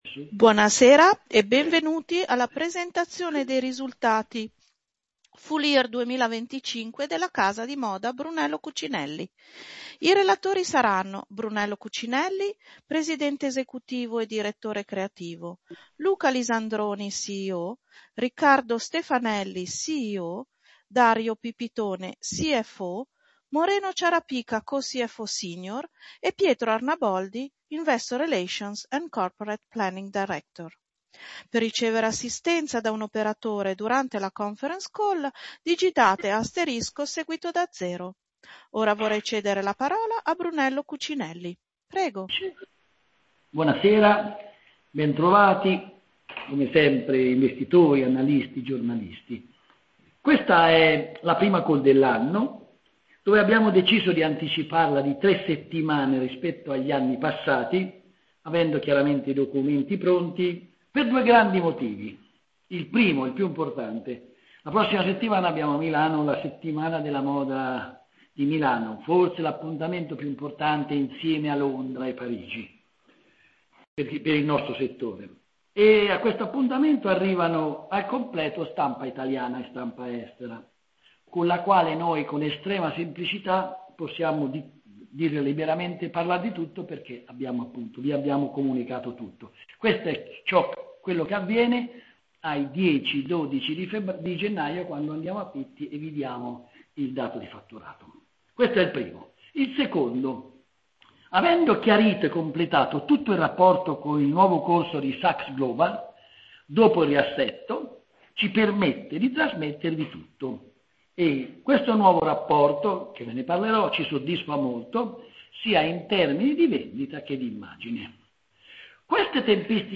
Conference call risultati FY 20252.mp3